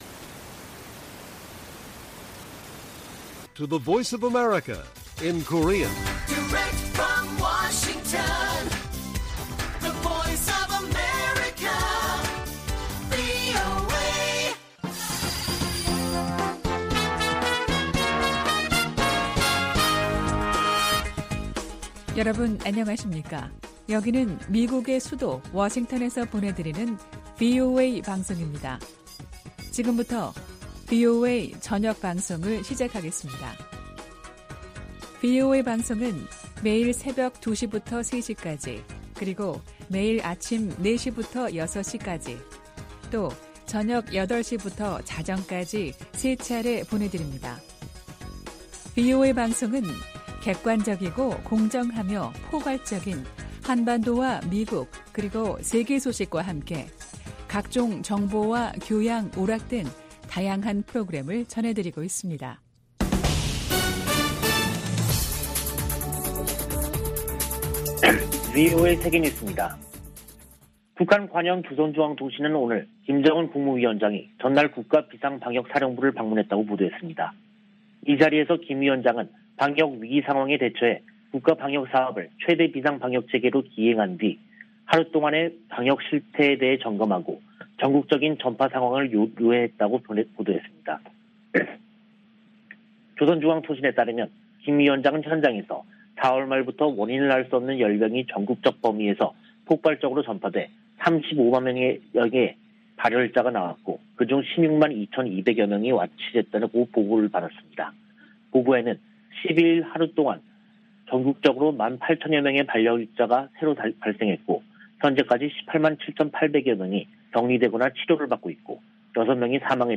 VOA 한국어 간판 뉴스 프로그램 '뉴스 투데이', 2022년 5월 13일 1부 방송입니다. 백악관은 북한이 이달 중 핵실험 준비를 끝낼 것으로 분석했습니다.